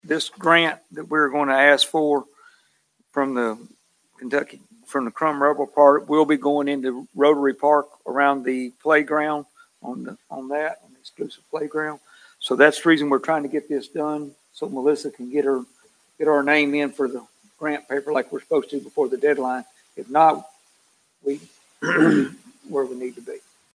In addition, Mayor J.R. Knight shared information about a Kentucky Waste Tire Crumb Grant.